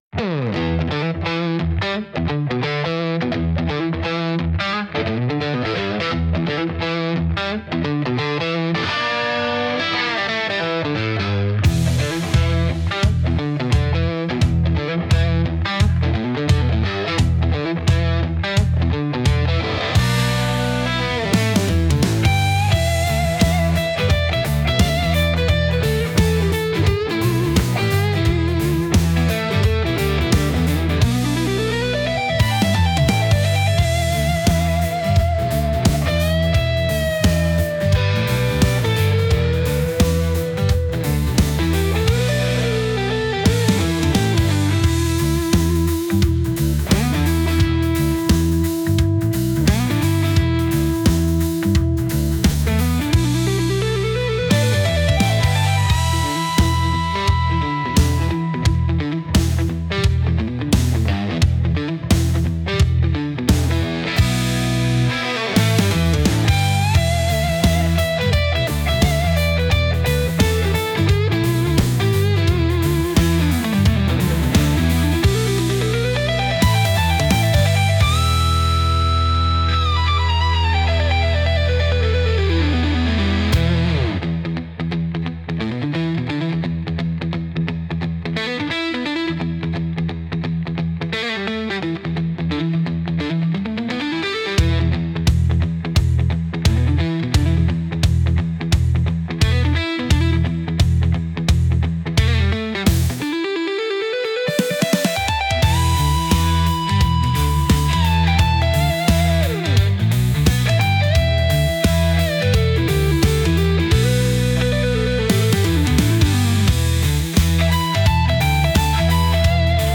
Instrumental Real Liberty Media Station Music 8_33